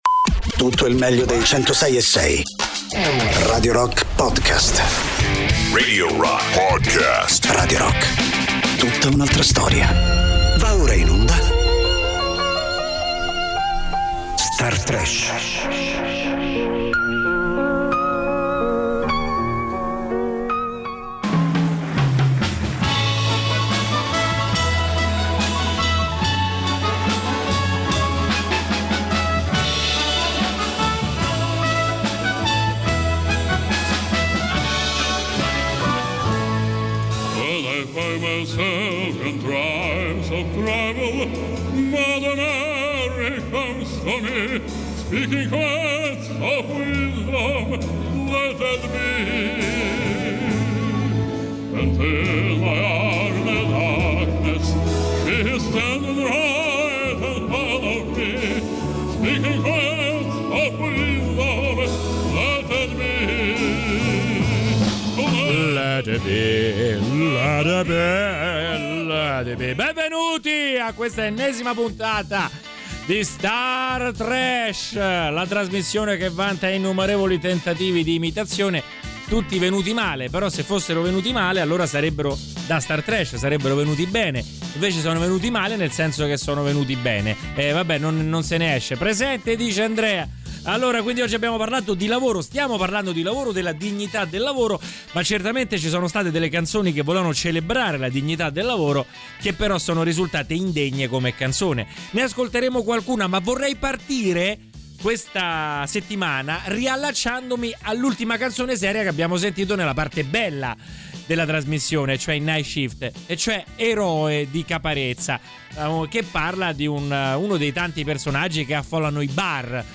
Star Trash: Vita da bar (ascoltando il country-rock) (30-04-24)